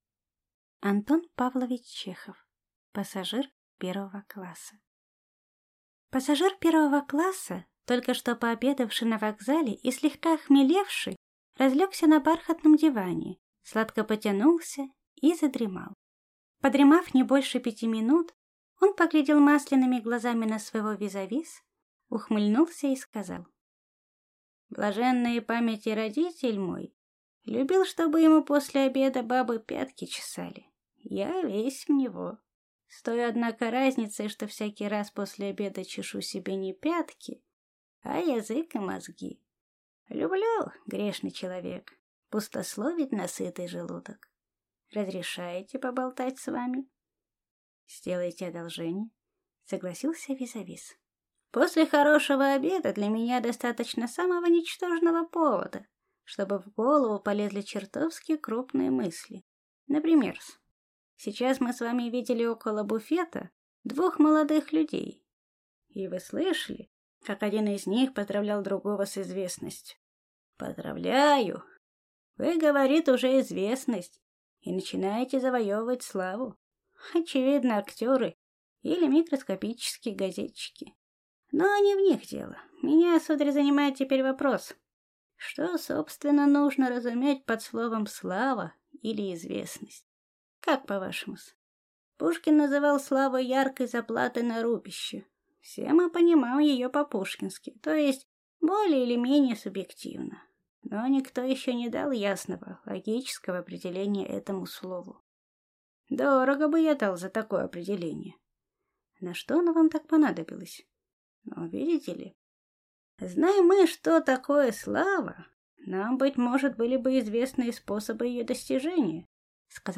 Аудиокнига Пассажир 1-го класса | Библиотека аудиокниг